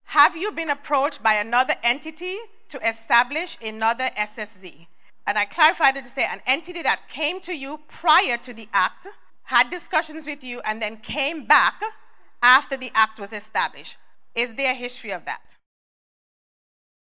On Tuesday Oct. 28th, during a townhall meeting, this question was posed to the NIA by a member of the audience: